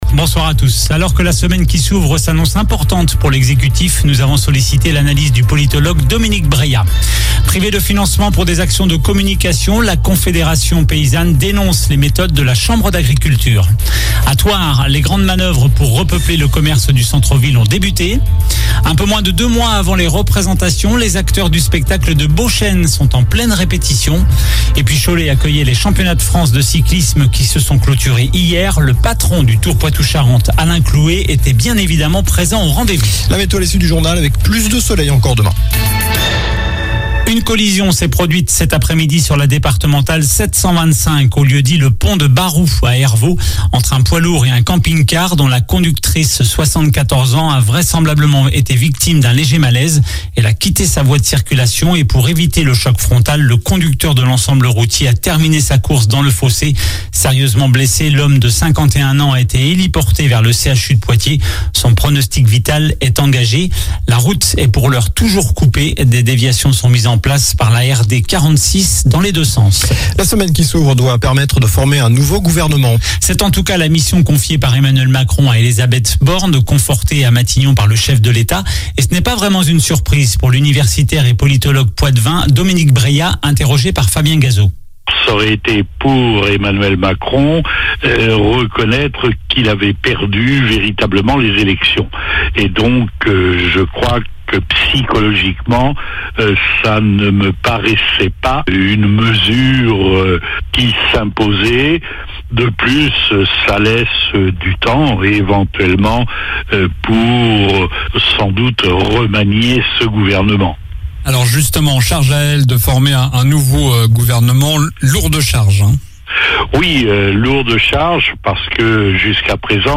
Journal du lundi 27 juin (soir)